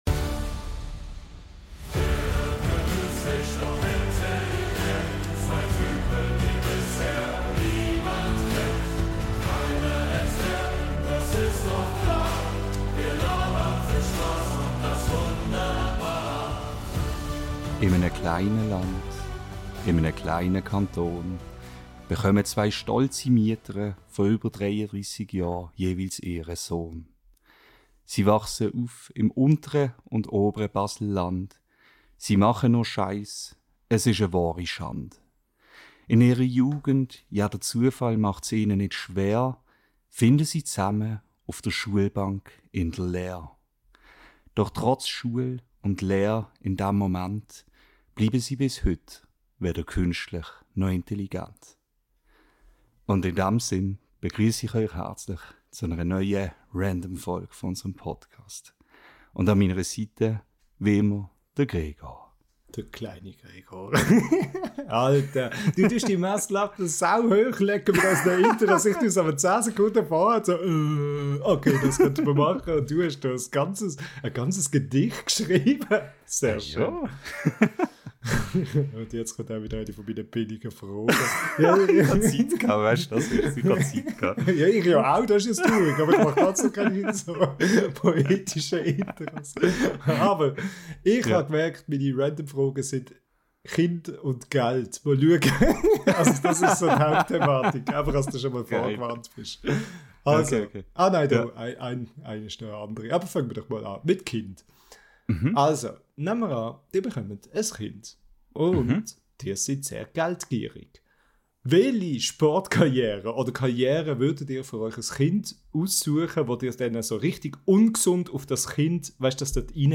Ganz einfach: reinhören in die neueste Ausgabe unseres schweizerdeutschen Podcasts.